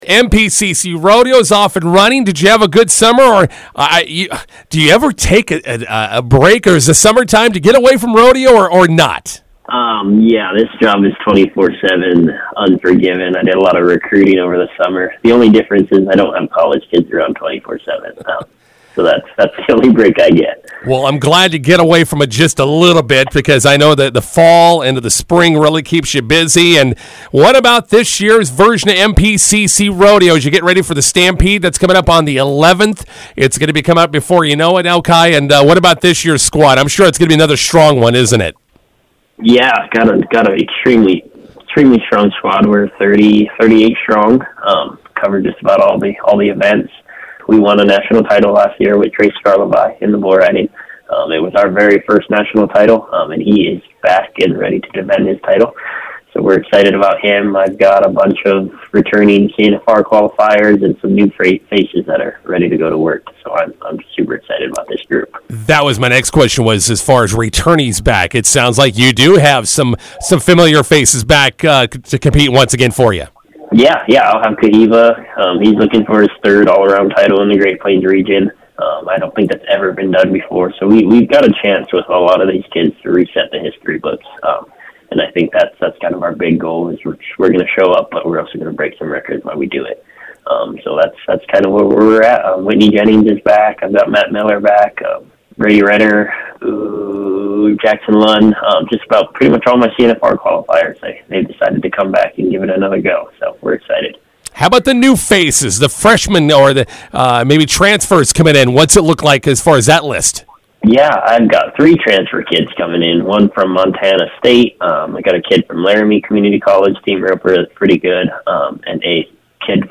INTERVIEW: Mid-Plains Community College Rodeo kicks off their fall season this week in River Falls.